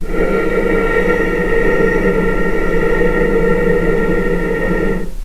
vc-A#4-pp.AIF